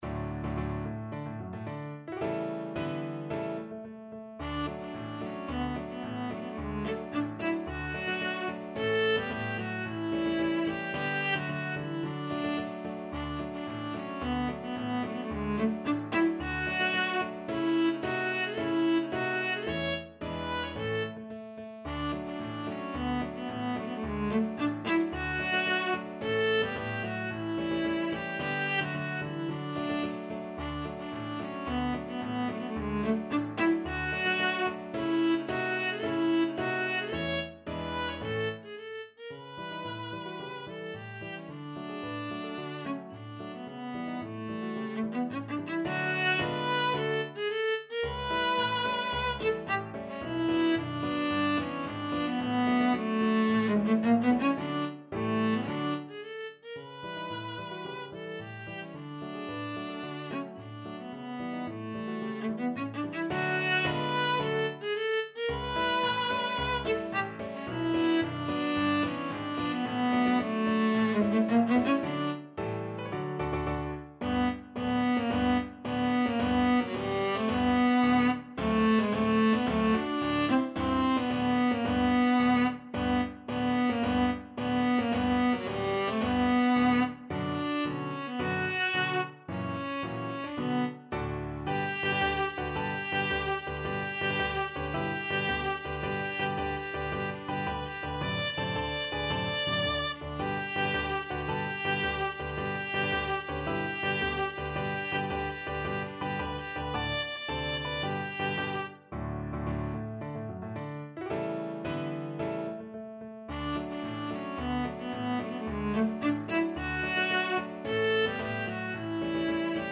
Viola
D major (Sounding Pitch) (View more D major Music for Viola )
2/2 (View more 2/2 Music)
March =c.110
Classical (View more Classical Viola Music)